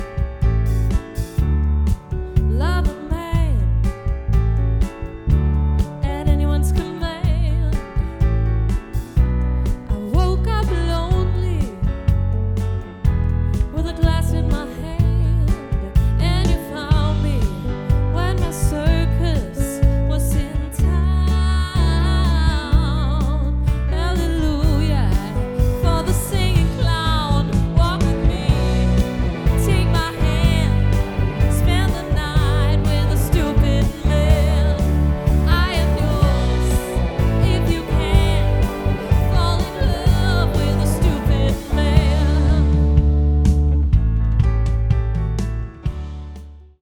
Vokal
Guitar
Keys
Trommer
• Coverband